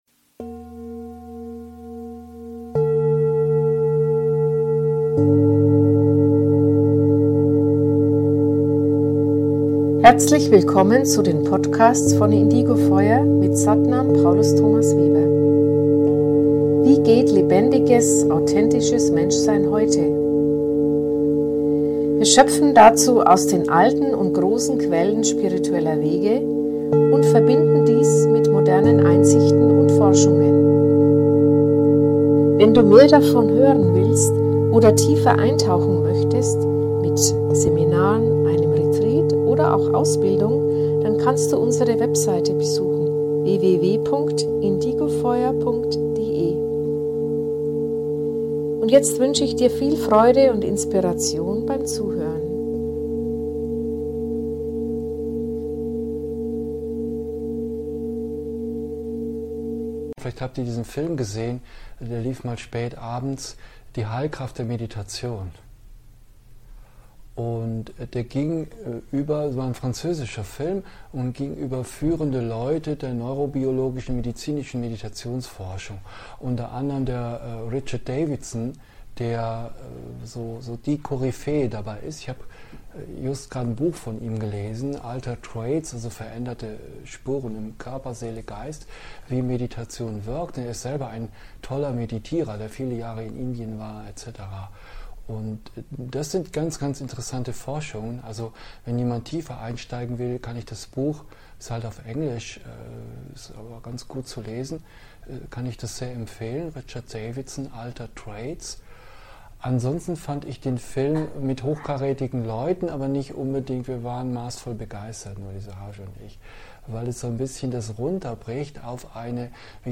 Dies ist ein Live-Mitschnitt aus einem Meditations-Wochenende.